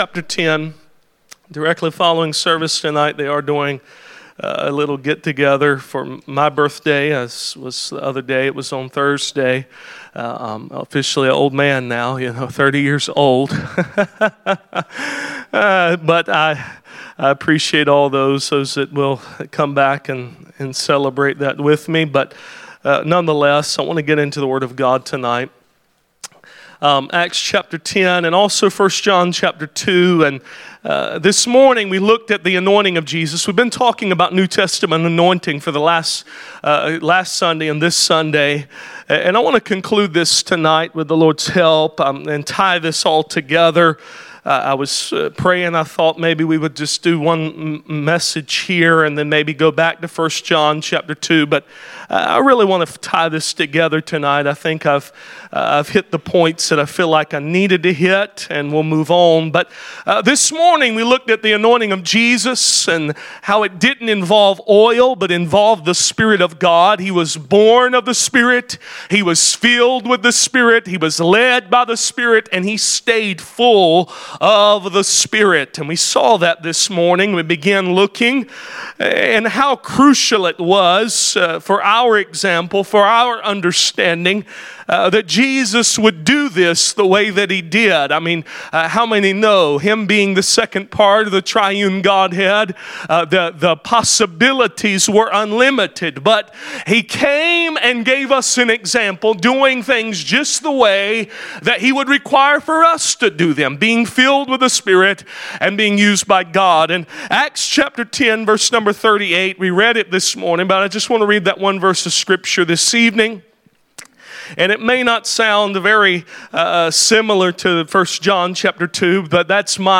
Passage: Acts 10:39, 1 John 2:18, 20, 27 Service Type: Sunday Evening « The process of Jesus’ anointing The Death of a Son